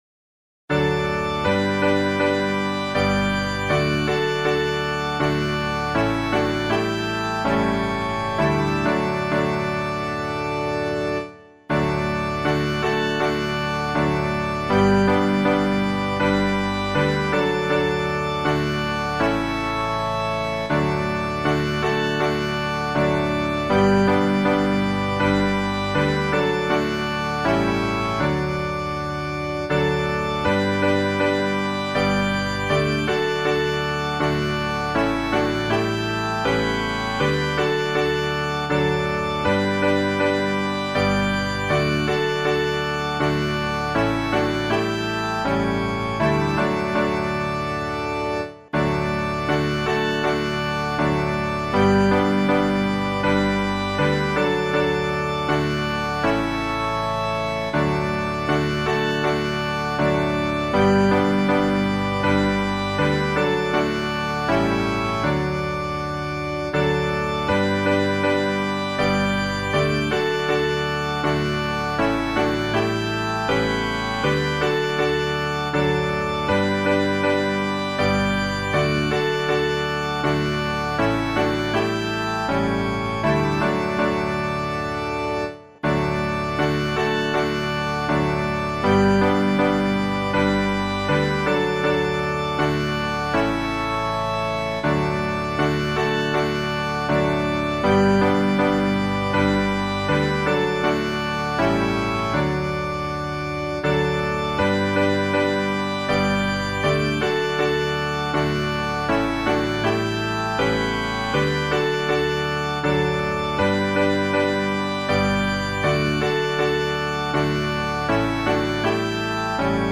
Instrumental accompaniment